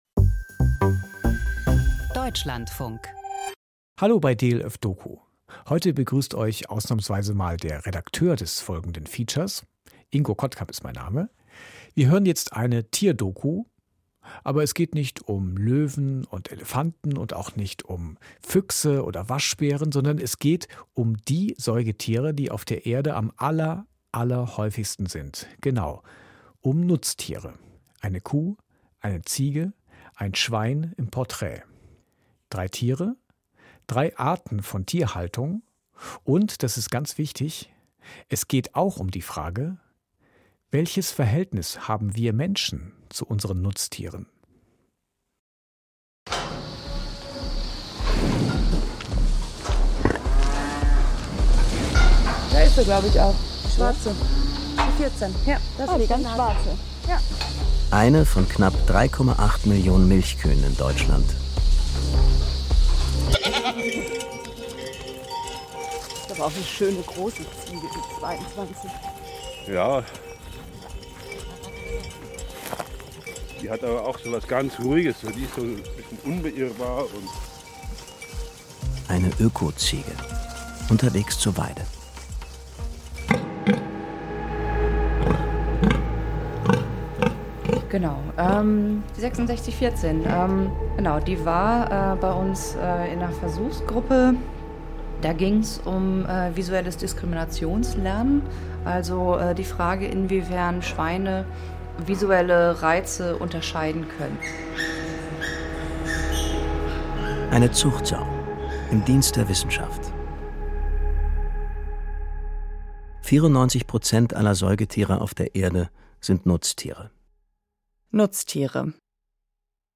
Hörspiel: Psychogramm einer Großstadtjugendlichen